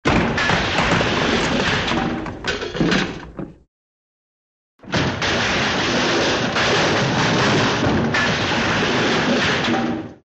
Звуки погрома
На этой странице собрана коллекция звуков погрома: треск ломающейся мебели, звон разбитого стекла, грохот падающих предметов.
Погром в квартире